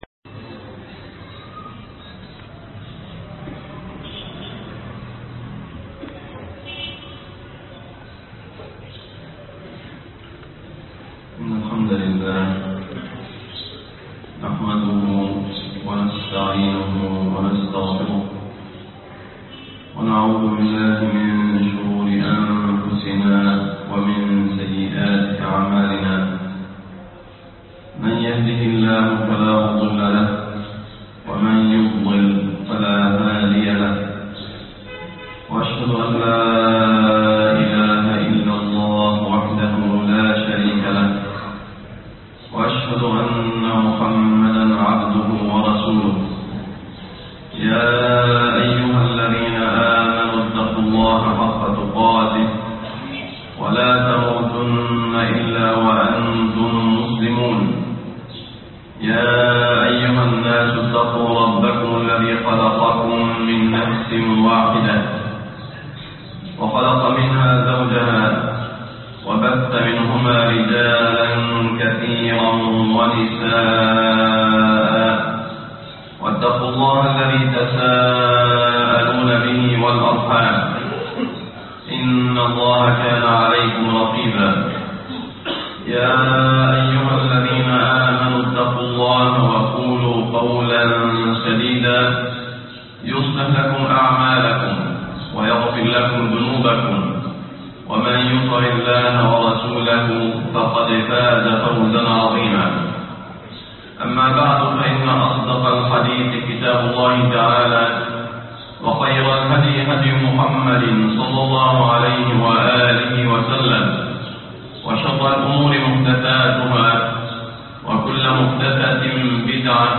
أهل الجنة الهينون اللينون - خطب الجمعة